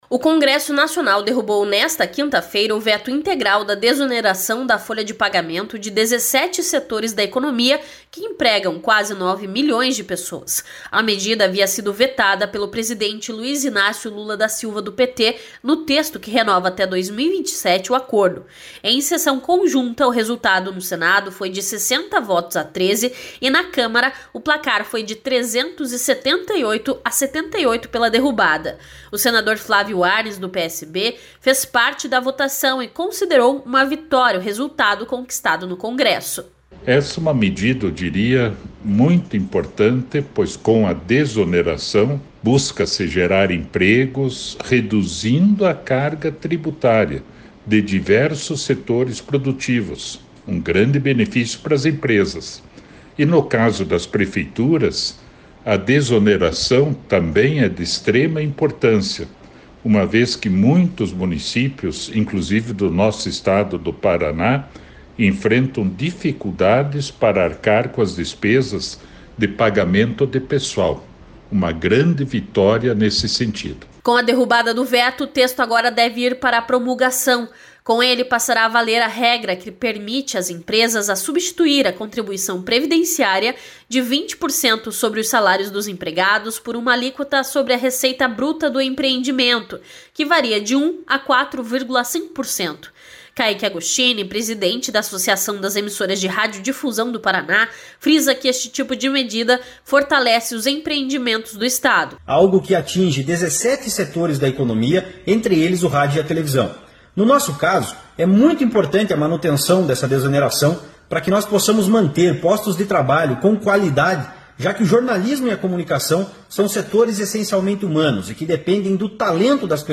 O senador Flávio Arns do PSB, fez parte da votação e considerou uma vitória o resultado conquistado no congresso.